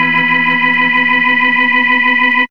5406R ORGCHD.wav